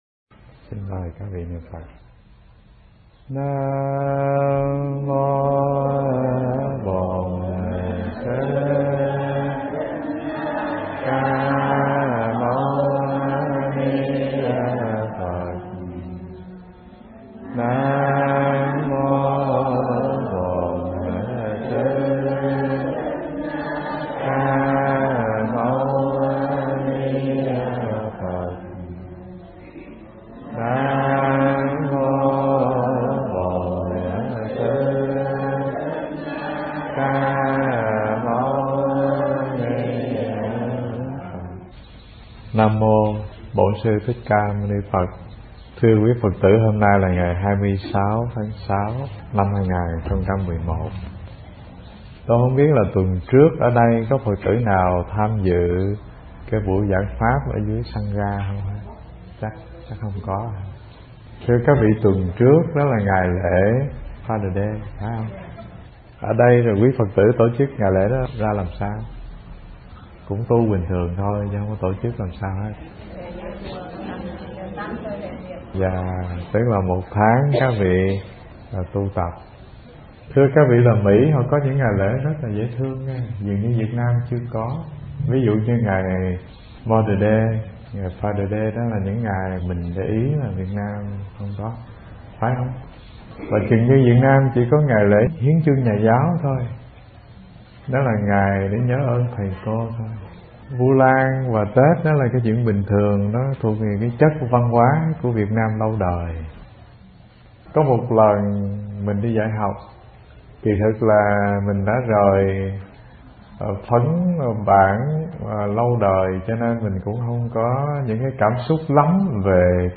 Nghe Mp3 thuyết pháp Thuần Hưởng Đời Sống